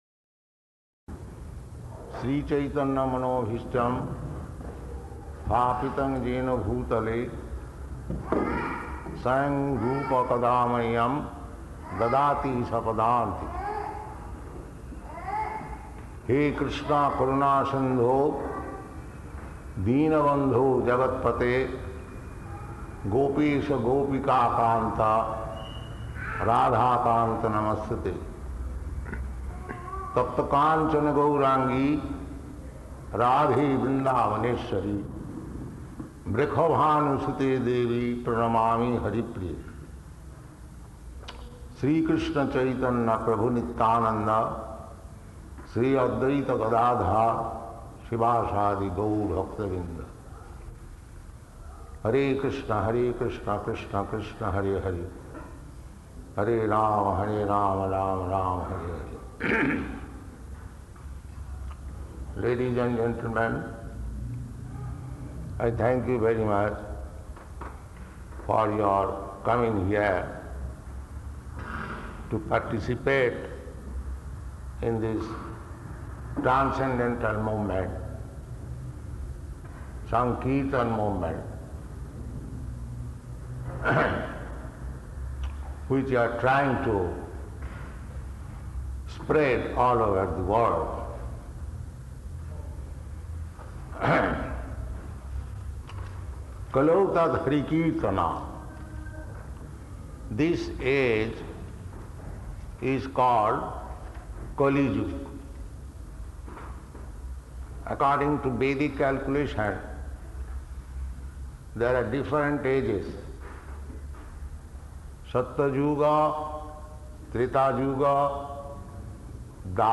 Type: Lectures and Addresses
Location: London